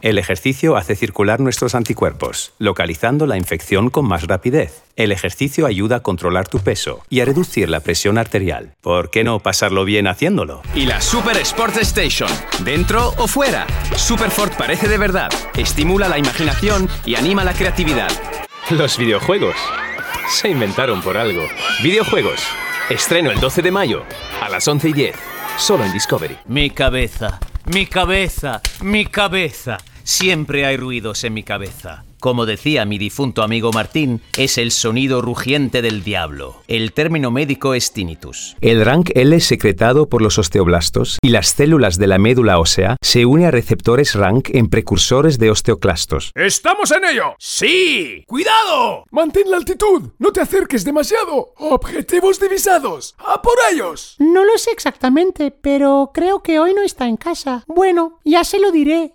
Home Studio
• Neumann TLM 103 microphone
• Professionally soundproofed recording studio